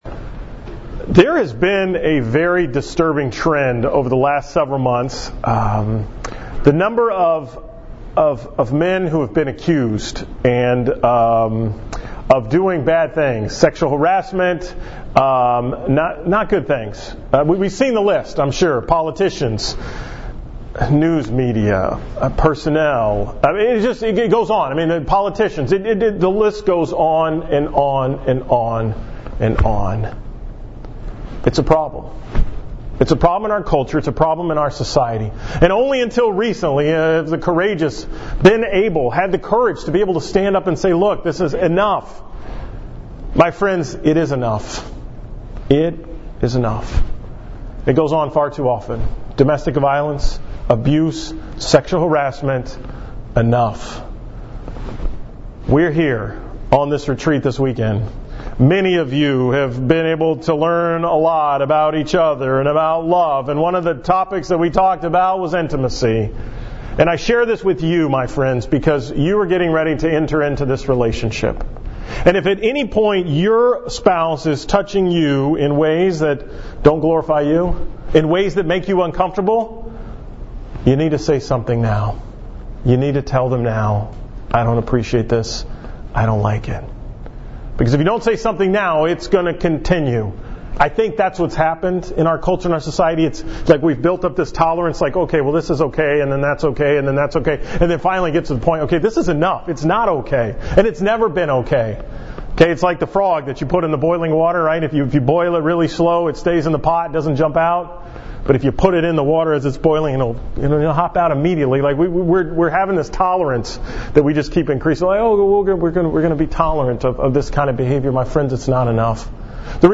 From the Engaged Encounter Retreat on December 10, 2017